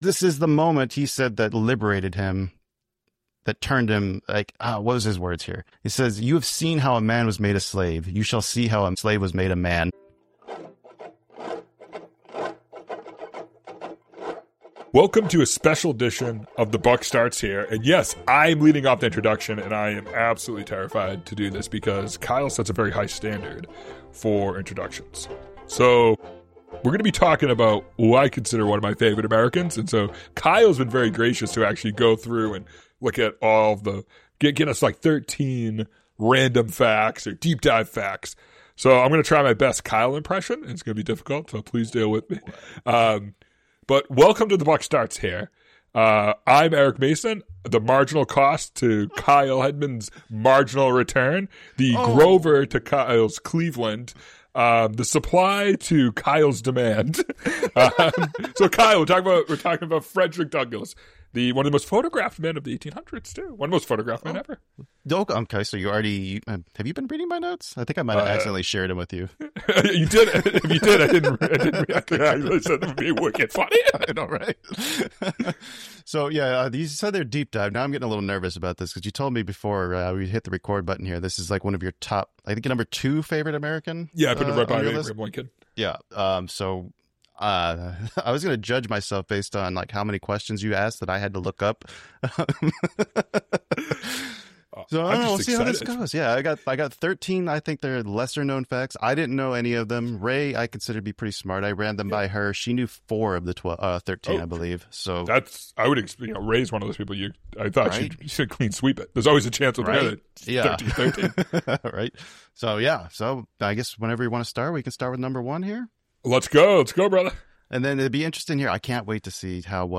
Buck Wild: Frederick Douglass Subtitle: Read, Wrote, Won Program Type: Regular Show